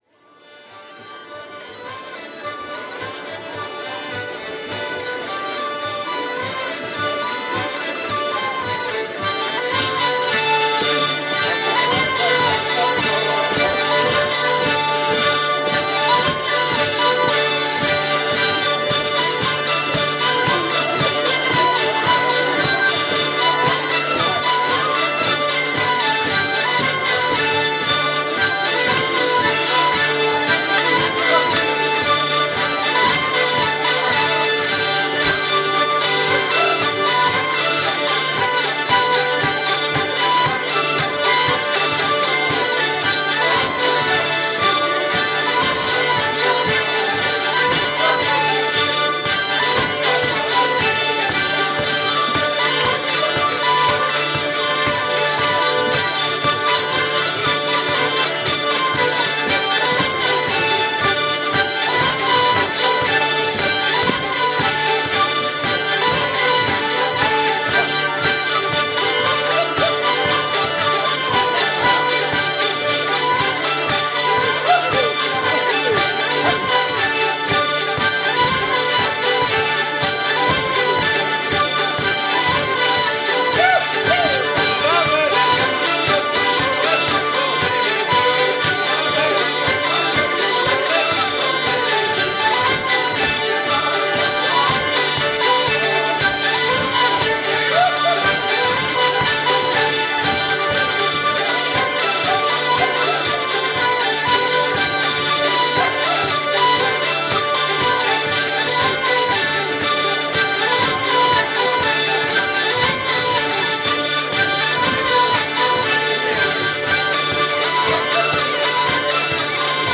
:mp3:2013:soiree_stagiaires
17_passepieds-vielles.mp3